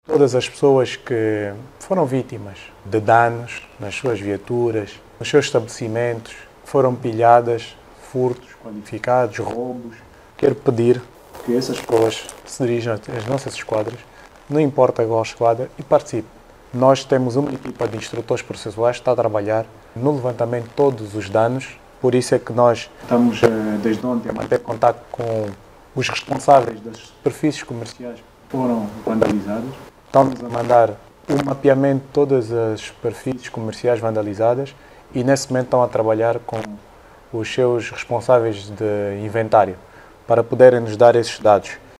A Polícia Nacional, está a preparar expedientes para a recolha de informação sobre os danos causados durante os dias de arruaça na província de Luanda. O director-adjunto Nacional de Operações, Lázaro da Conceição, diz estarem já arroladas mais de trinta viaturas que foram destruídas.